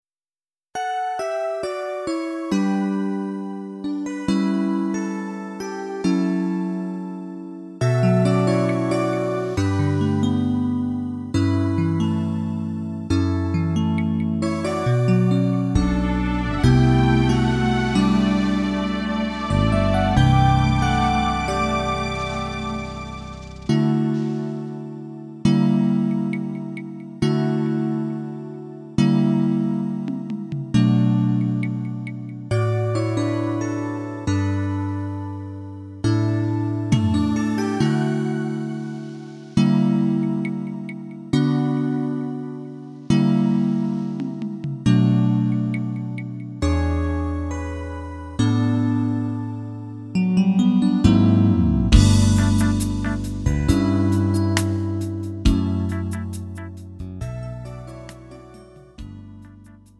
음정 -1키 4:31
장르 가요 구분 Lite MR